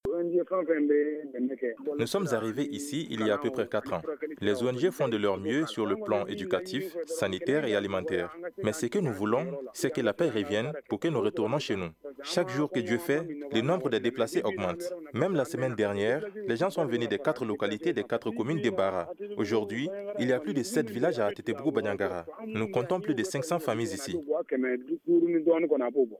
Un responsable des déplacés qui a requis l’anonymat.